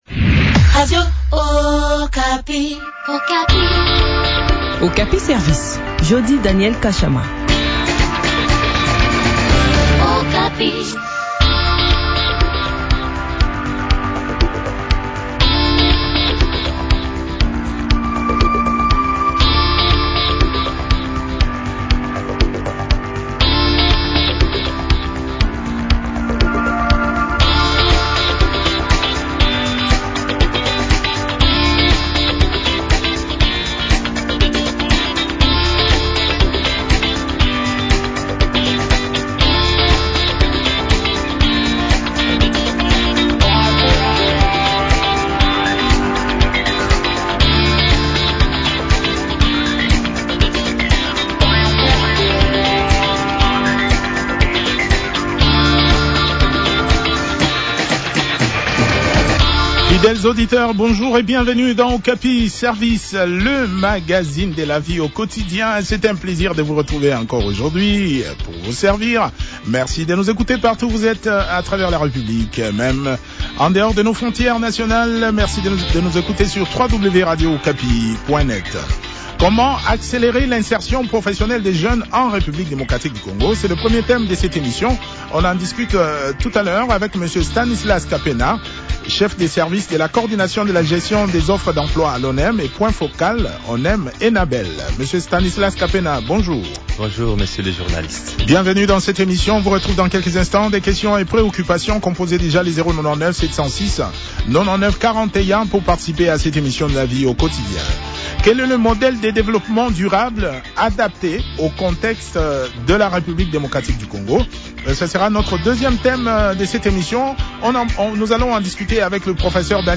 s’entretient sur ce sujet